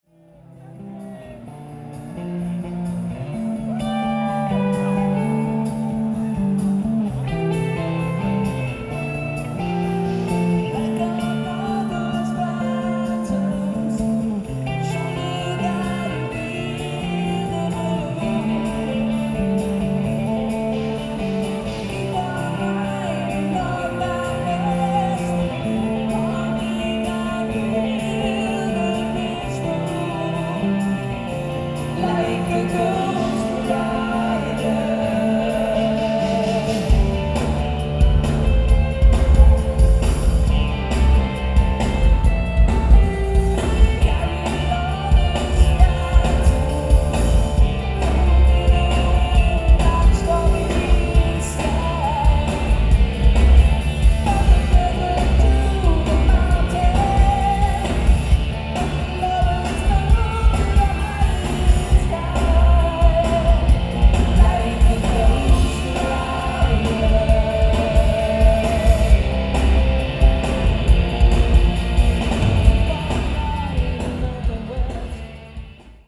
Source: Audience (Master DAT)
Venue: Montage Mountain Amphitheater
Equipment List: Sony PCM-M1, Soundman OKM II RKS mics
Recorded fourth row, a little to stage right of Alex.
Sound samples (shitty mp3 but they will have to do: